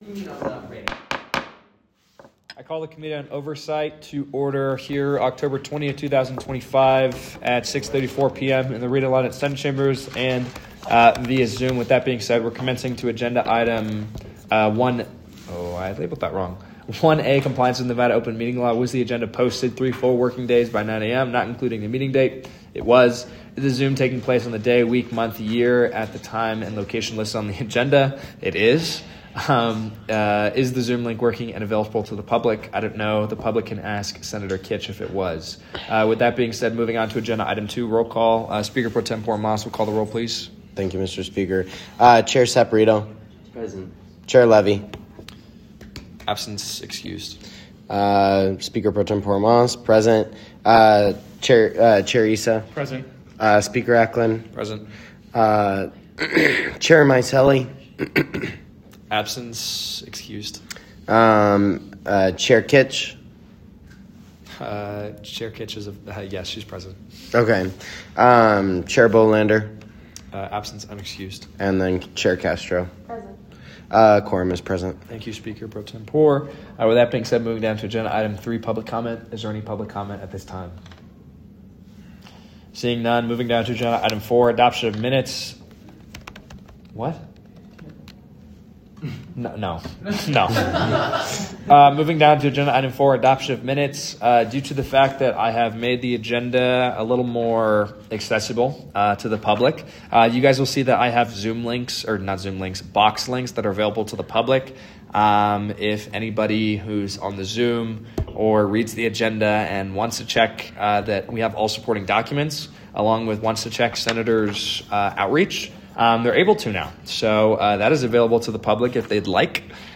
Meeting Type : Oversight Committee
Location : Rita Laden Senate Chambers